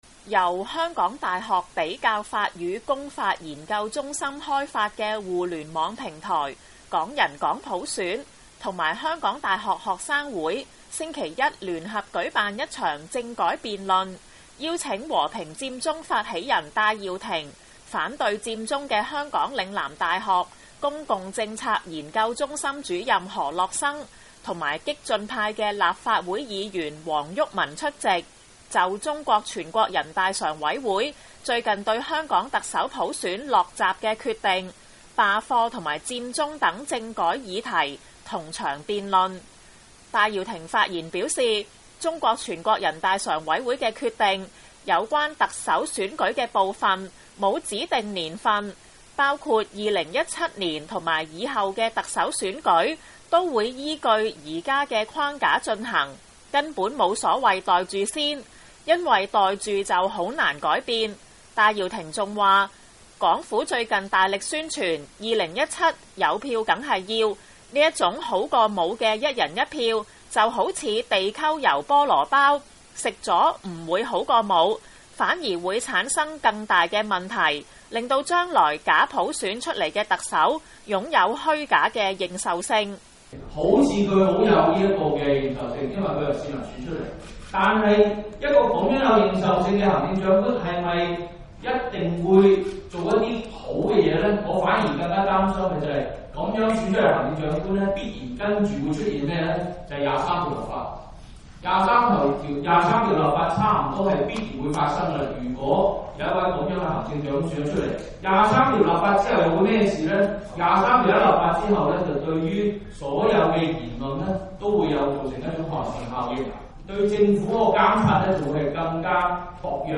香港佔中與反佔中政改辯論